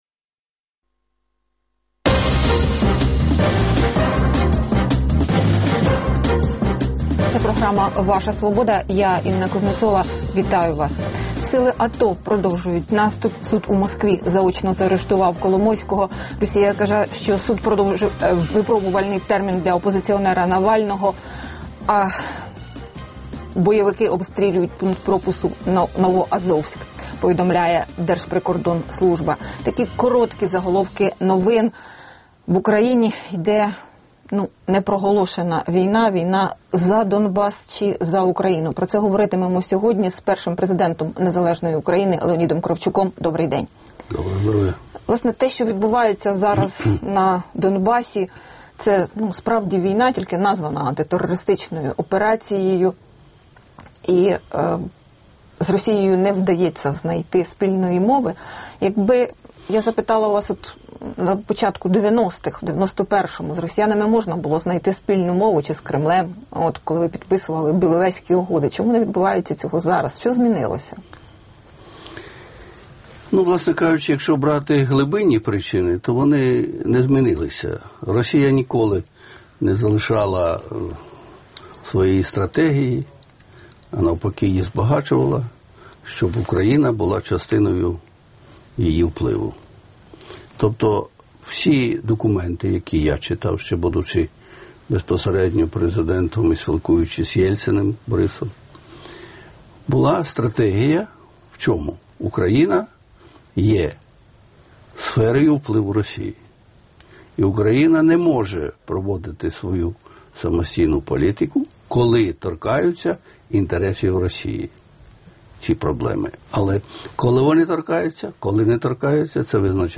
Гість: Леонід Кравчук, перший президент України